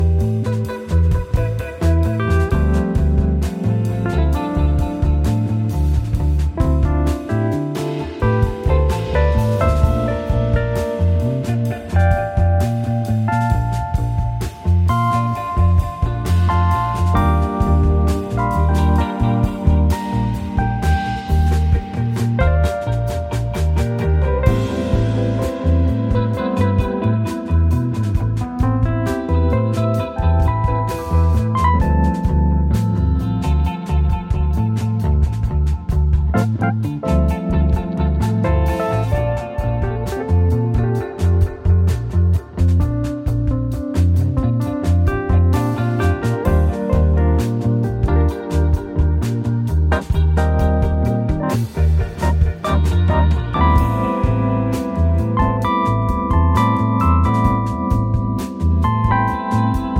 今回は、スイスの高地にあり、高級スキーリゾート地として知られる村レザンで6日間かけて録音したという作品。
清涼感や浮遊感を纏ったクールな現代ジャズ/フュージョンを繰り広げています。